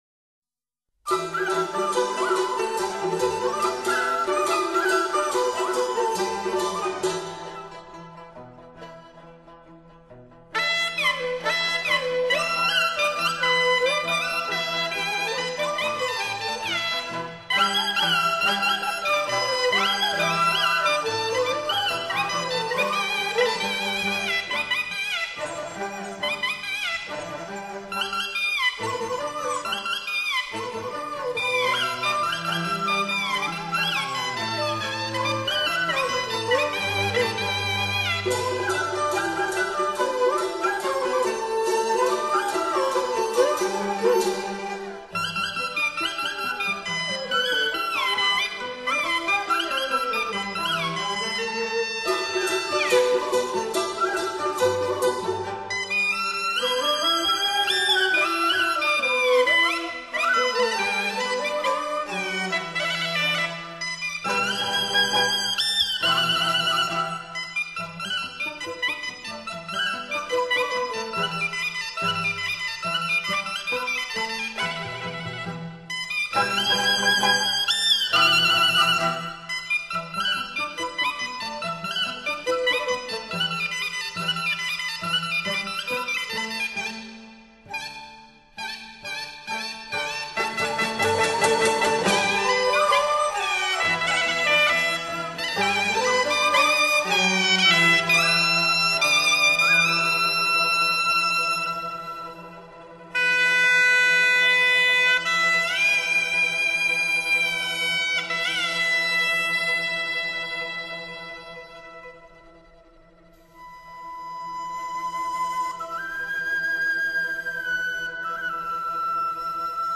唢呐